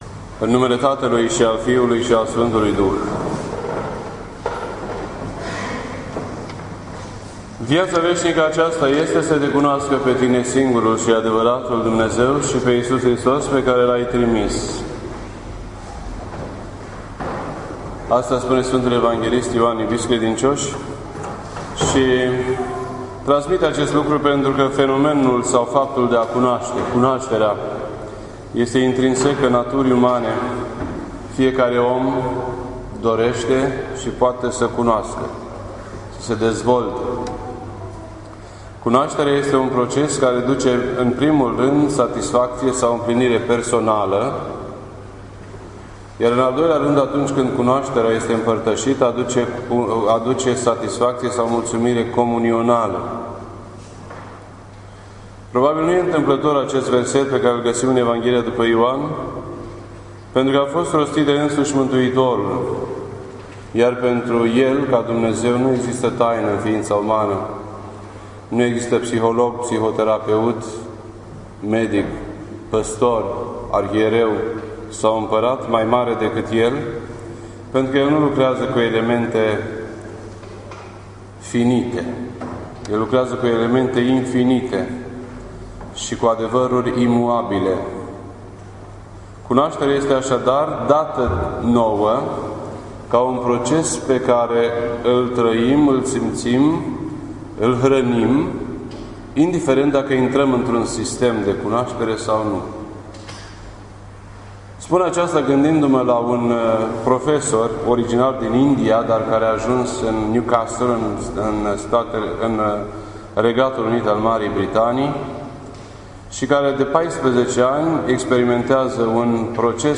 This entry was posted on Monday, July 7th, 2014 at 12:04 PM and is filed under Predici ortodoxe in format audio.